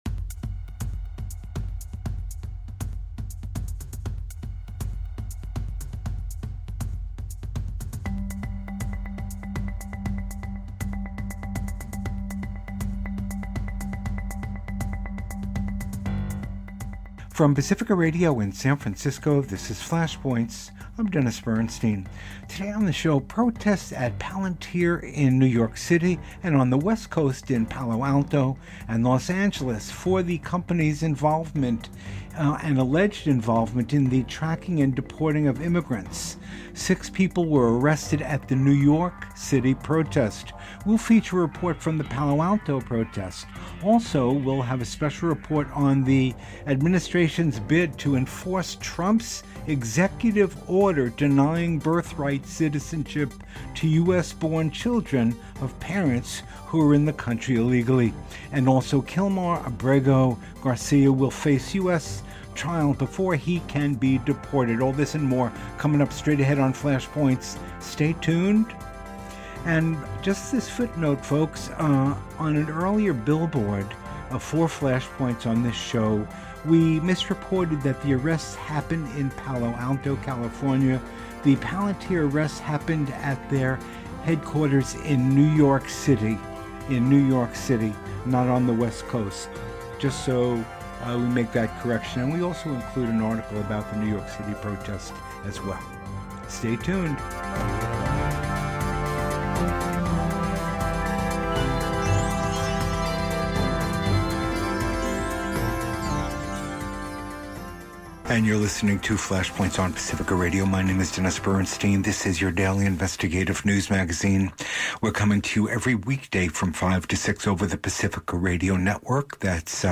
An award winning front-line investigative news magazine focusing on human, civil and workers rights, issues of war and peace, Global Warming, racism and poverty, and other issues.